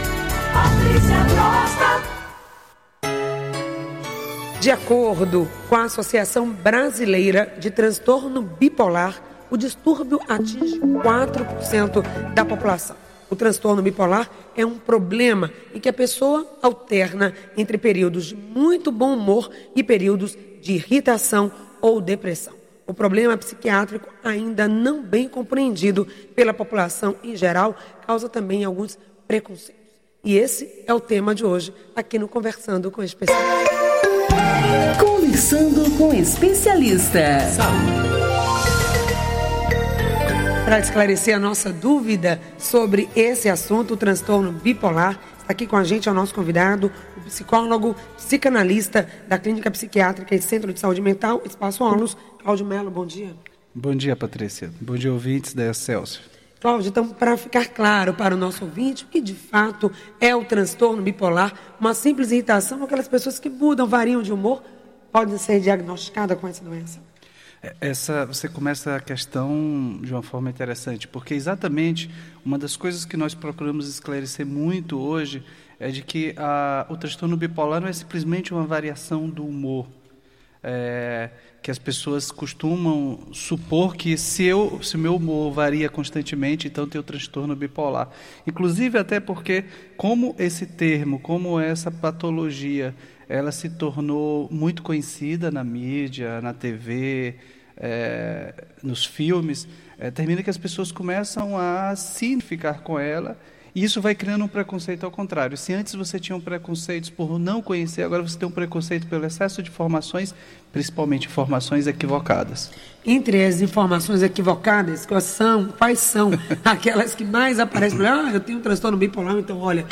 O transtorno bipolar, conhecido até pouco tempo como doença maníaco-depressiva, é uma doença psiquiátrica que se caracteriza por variações acentuadas de humor, com crises repetidas de depressão e “mania”. Ouça a entrevista: